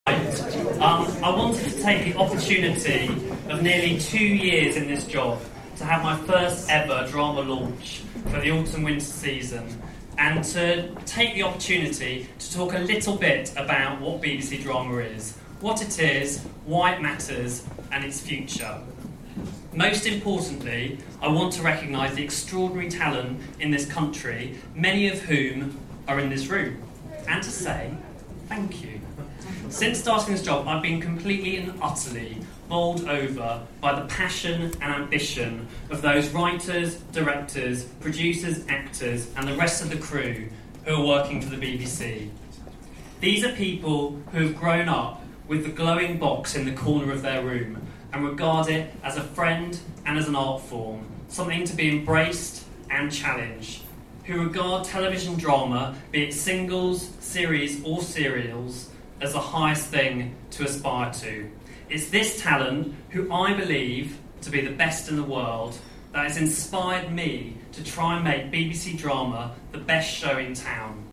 A rainy night in London town this evening for a special BBC Drama event.